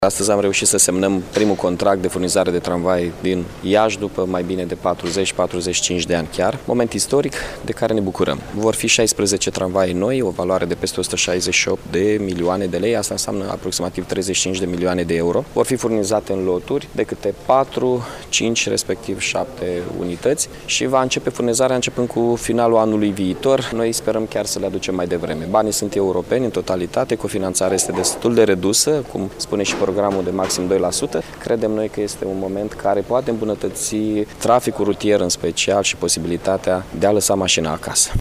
Primarul Iaşului, Mihai Chirica: